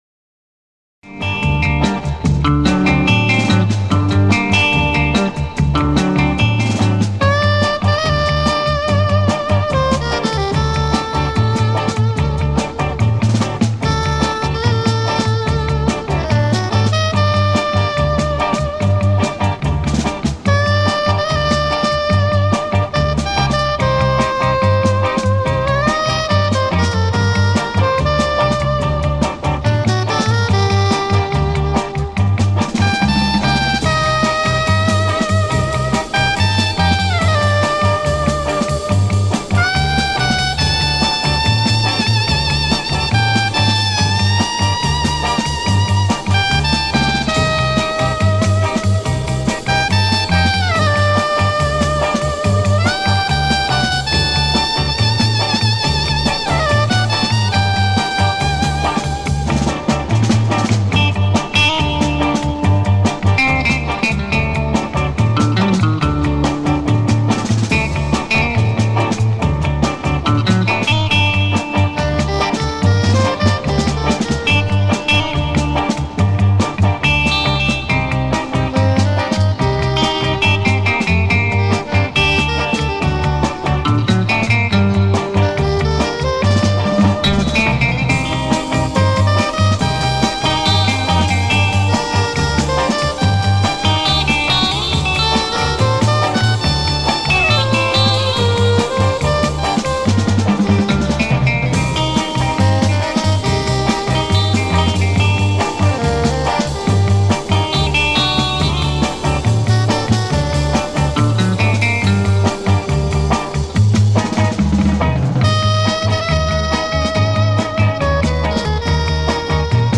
Приятная мелодия, похоже, что то из японской музыки.